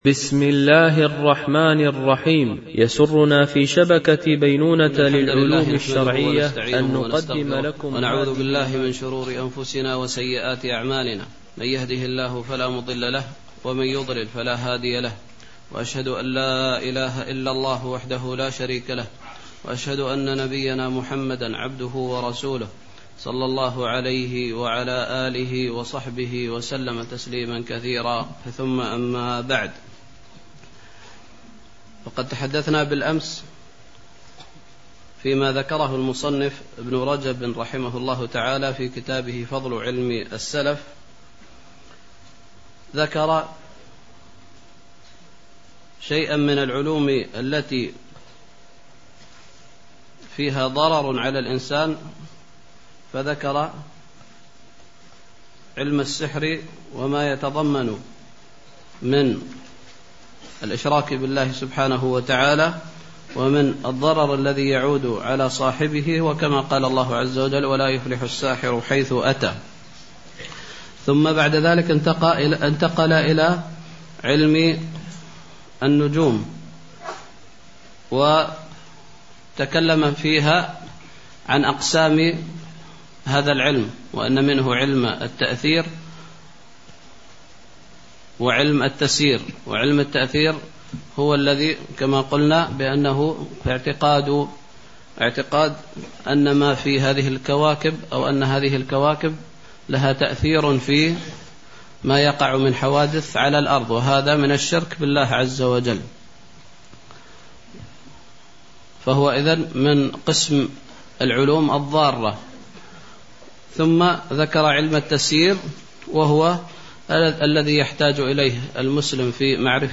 شرح فضل علم السلف على علم الخلف ـ الدرس 2